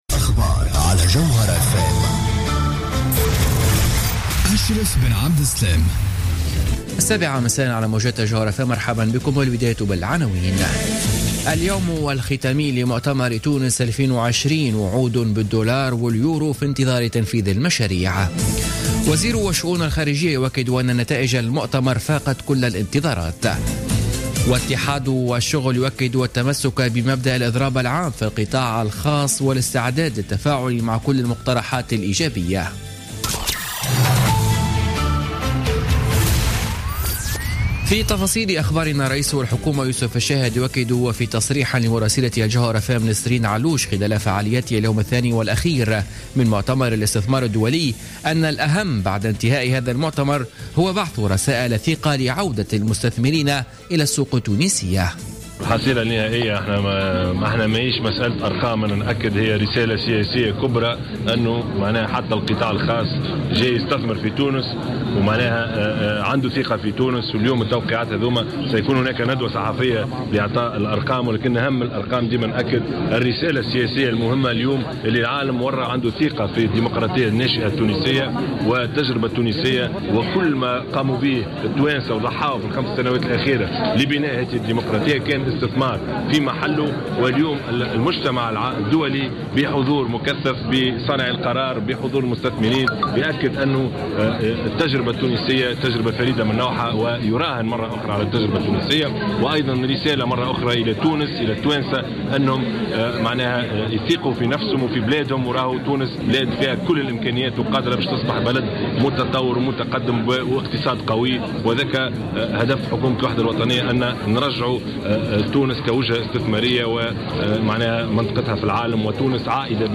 Journal Info 19h00 du mercredi 30 novembre 2016